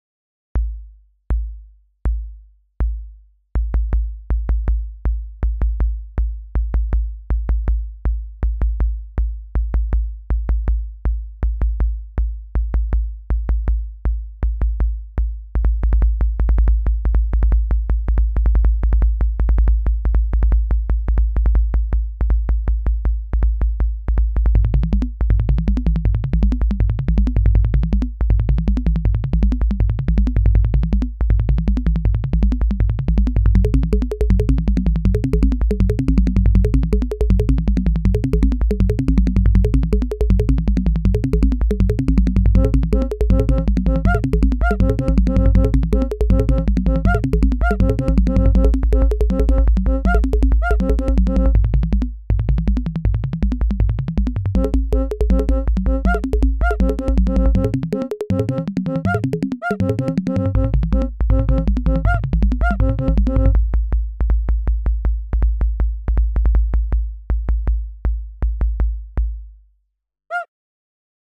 the beginning was a litle too quiet, but the end was realy like: huh? Laughing